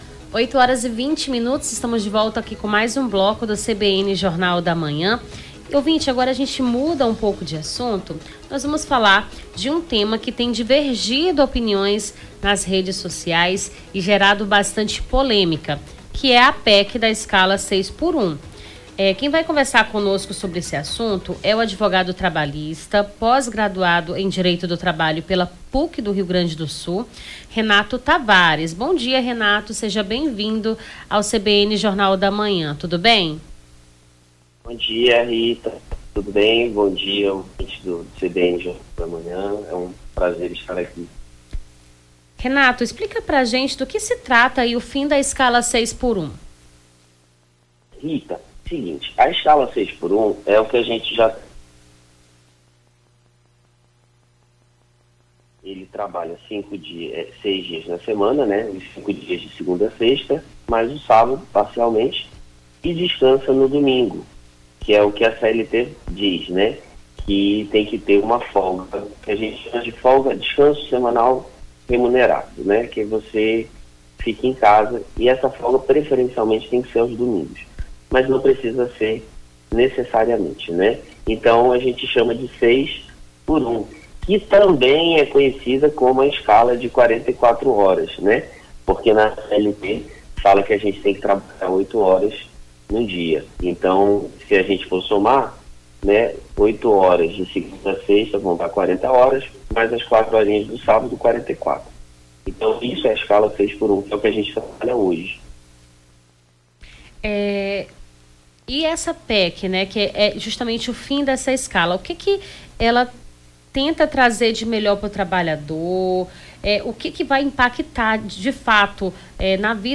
Advogado explica a PEC que busca pôr fim à escala de trabalho 6x1
ENTREVISTA PEC ESCALA 6X1